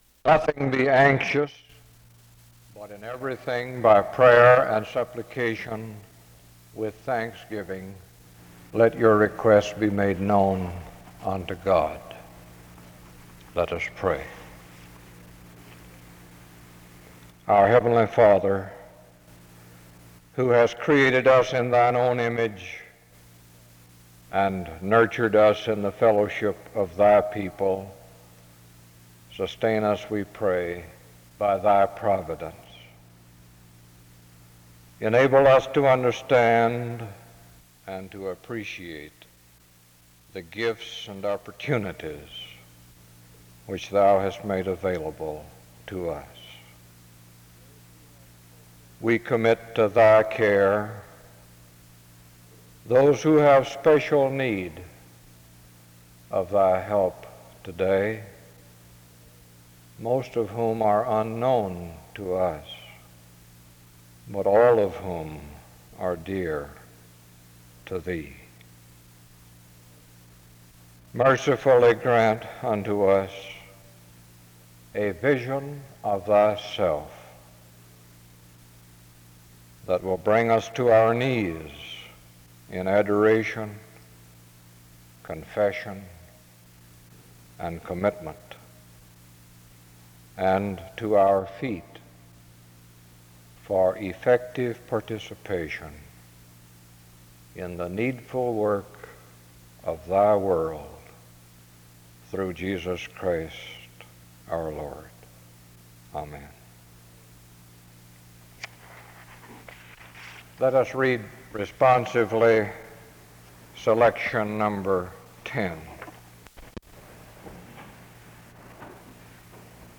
The service begins with an opening scripture reading and prayer from 0:00-1:46. Music plays from 2:03-4:16. An introduction to the speaker is given from 4:25-5:47.
Closing music plays from 21:17-22:15. A closing prayer is offered from 22:25-24:13.
SEBTS Chapel and Special Event Recordings - 1960s